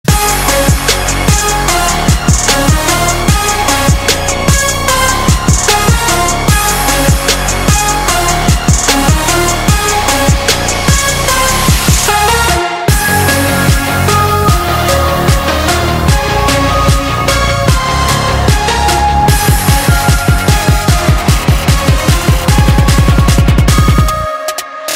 Nhạc Chuông DJ - Nonstop